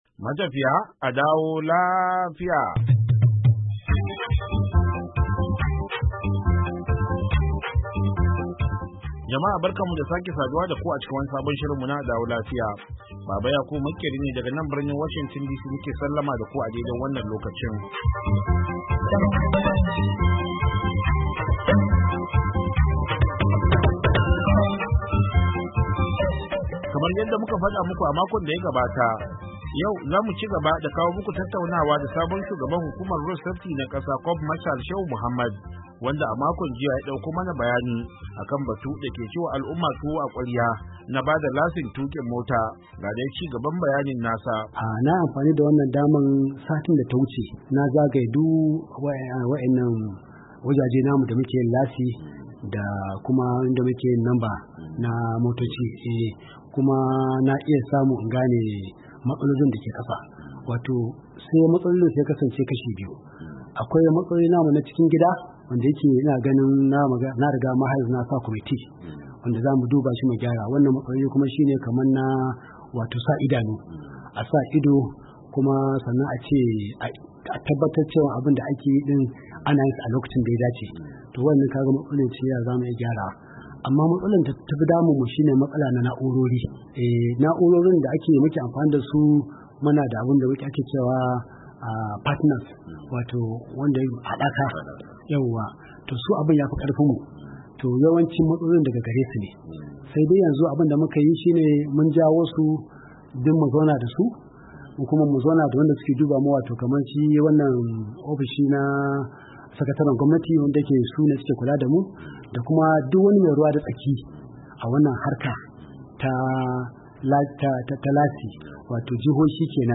A DAWO LAFIYA: Ci Gaba - Tattaunawa Ta Musamman Da Shugaban Hukumar Kiyaye Hadurra Ta Najeriya FRSC, Shehu Muhammad - Yuli 06, 2024